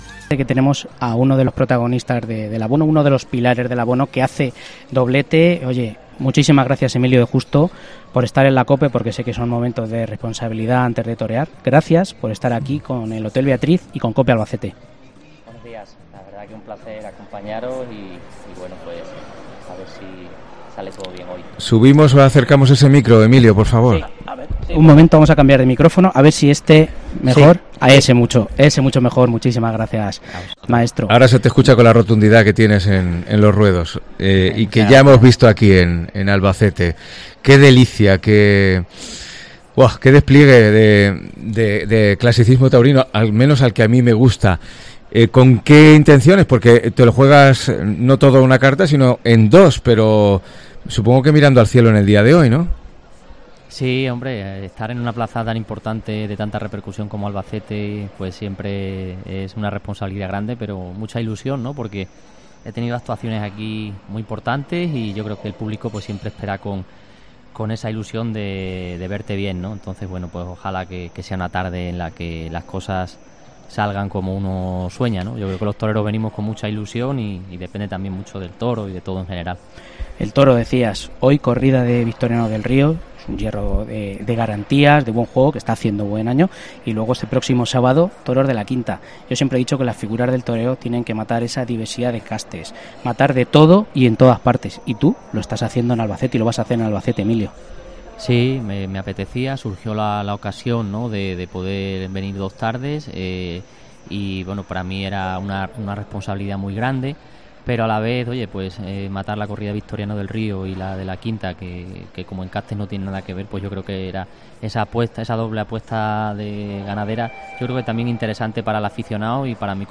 Dos de los tres toreros de la séptima de abono, han pasado por el micrófono de COPE
en el Hotel Beatriz,